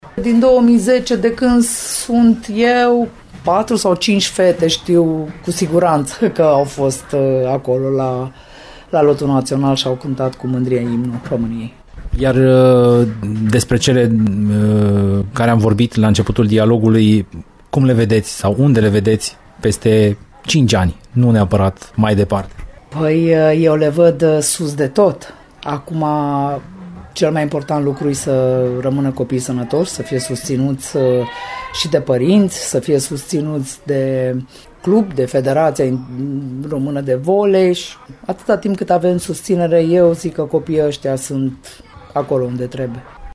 În ediția de duminică a Arenei Radio